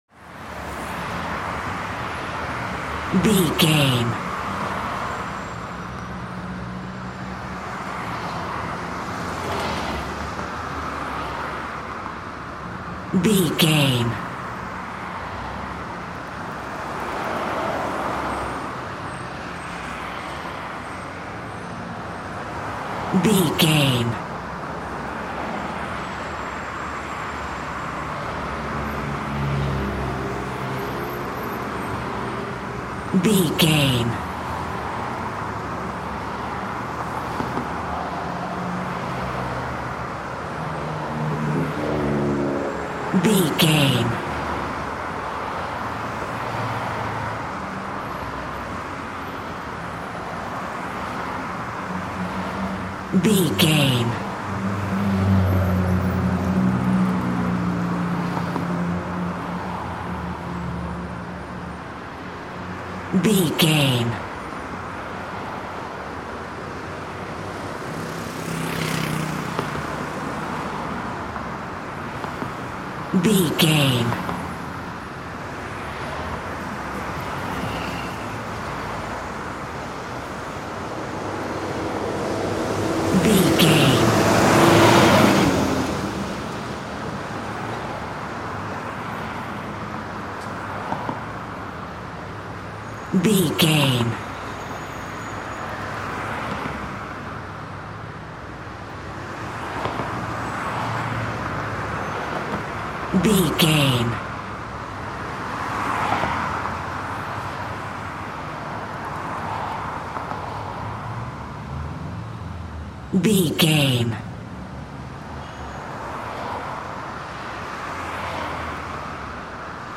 City avenue traffic
Sound Effects
urban
chaotic
ambience